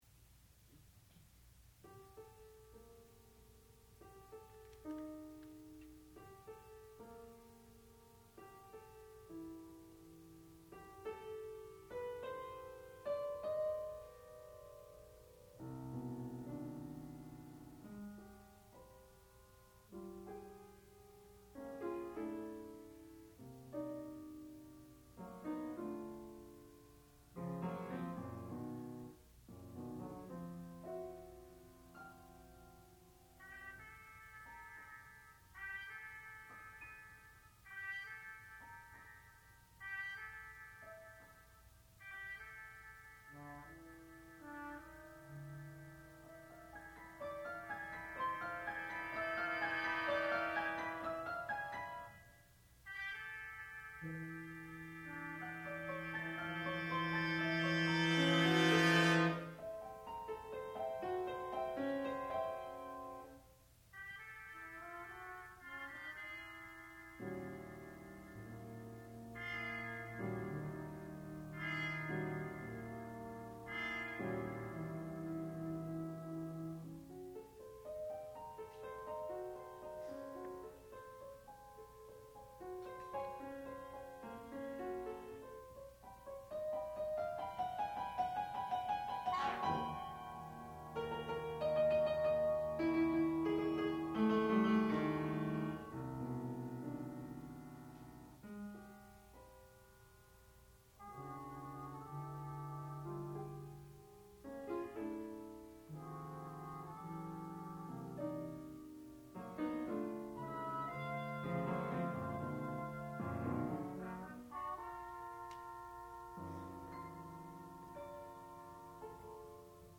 sound recording-musical
classical music
Student Recital
piano